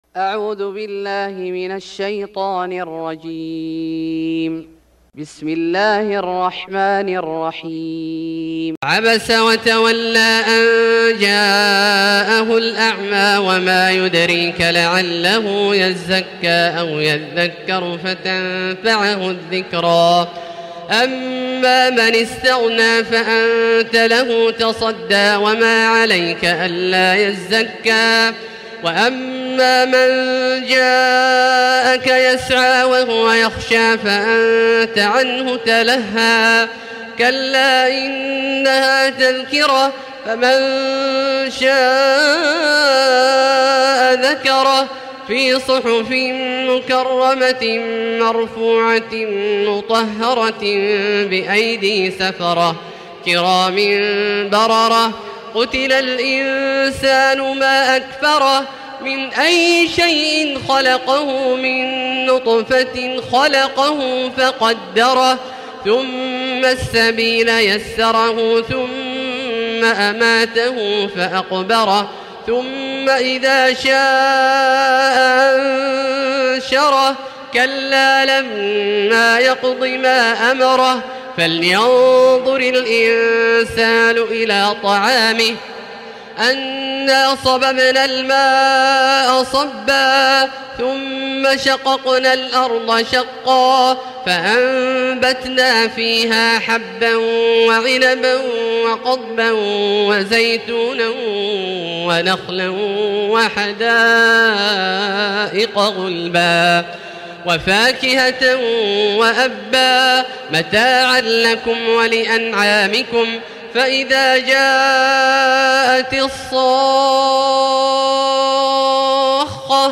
سورة عبس Surat Abasa > مصحف الشيخ عبدالله الجهني من الحرم المكي > المصحف - تلاوات الحرمين